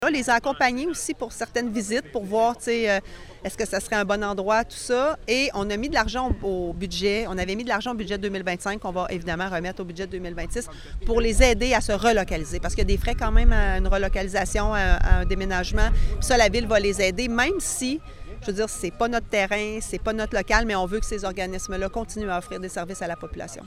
La mairesse de Nicolet, Geneviève Dubois, a assuré qu’ils pourront compter sur l’aide de la Ville.